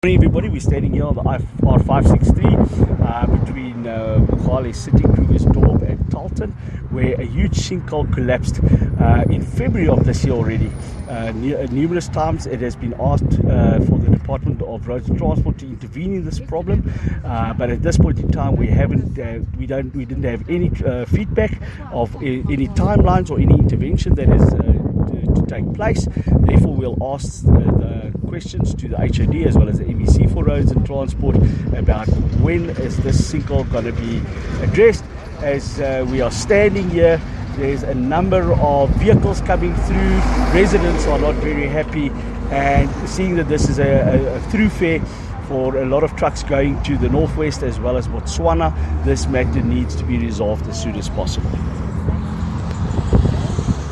Afrikaans soundbites by Evert Du Plessis MPL